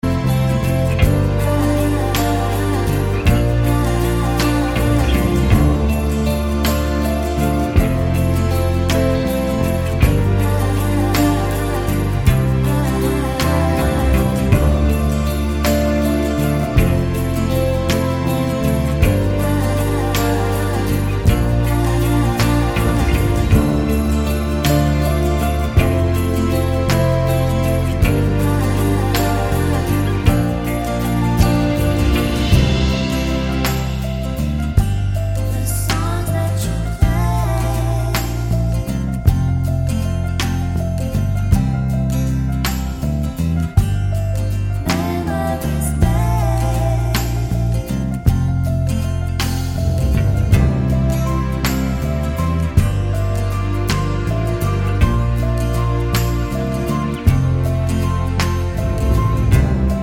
no Backing Vocals Duets 3:37 Buy £1.50